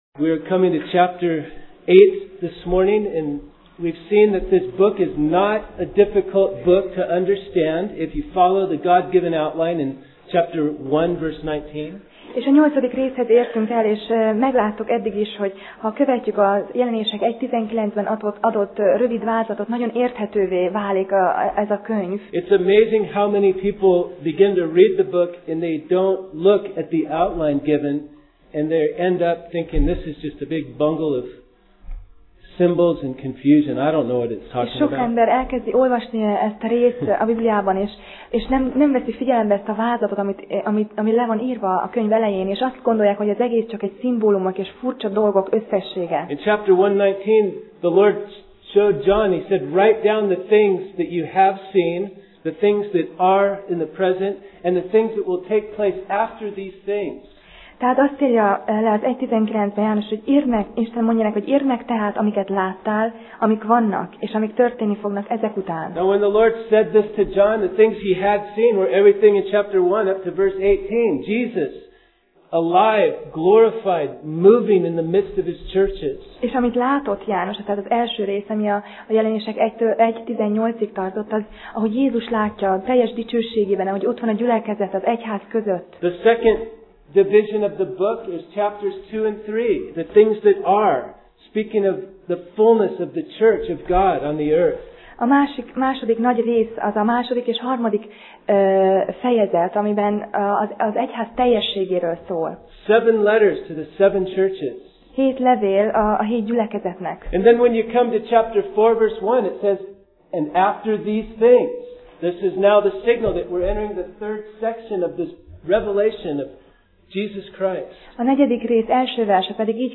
Jelenések Passage: Jelenések (Revelation) 8 Alkalom: Vasárnap Reggel